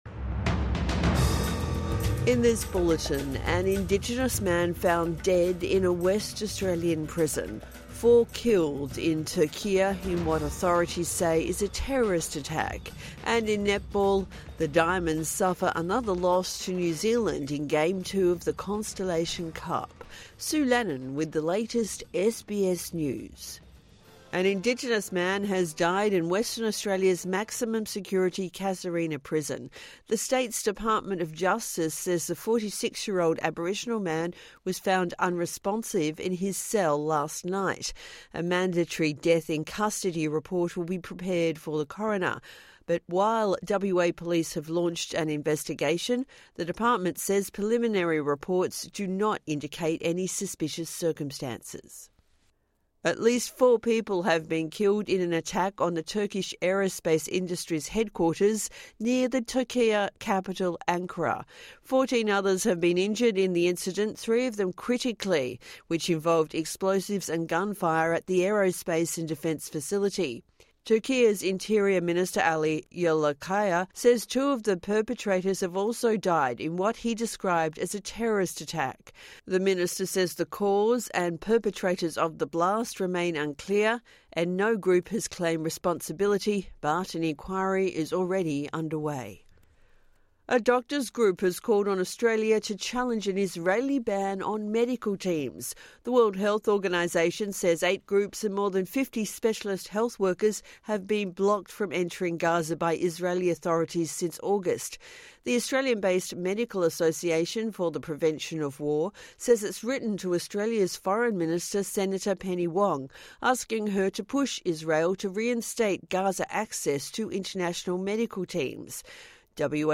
Morning News Bulletin 24 October 2024